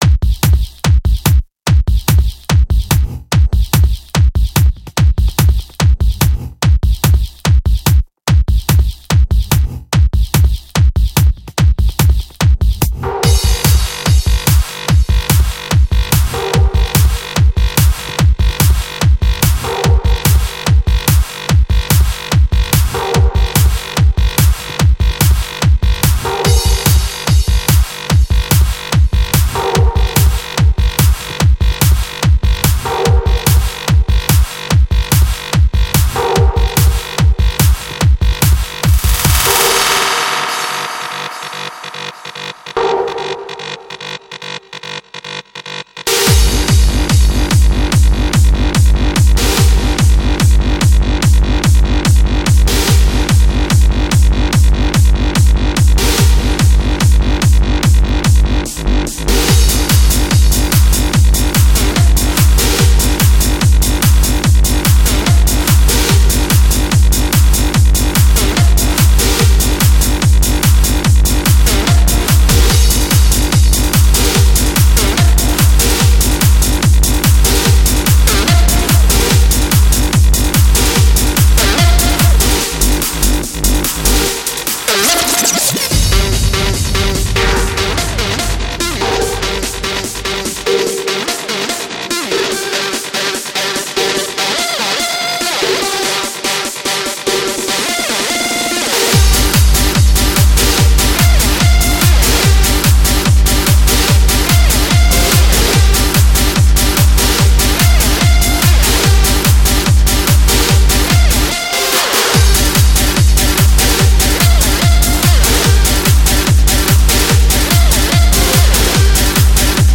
Жанр: Hard Trance